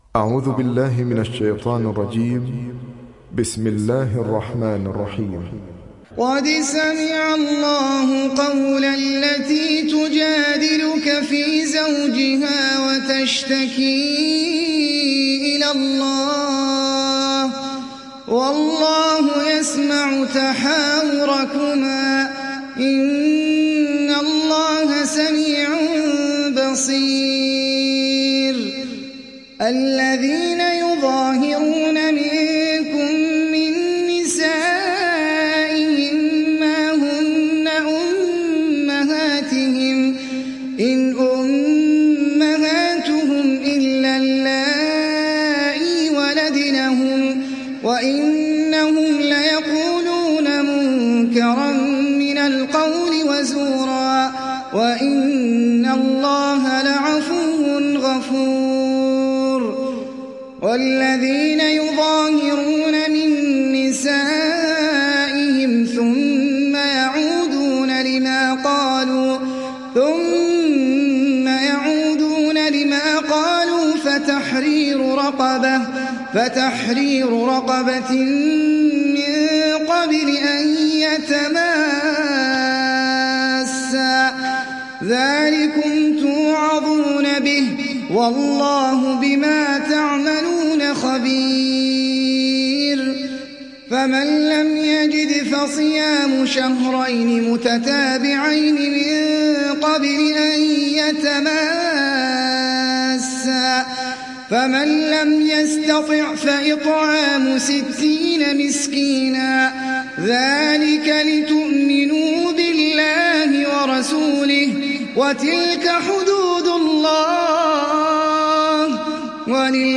Mücadele Suresi mp3 İndir Ahmed Al Ajmi (Riwayat Hafs)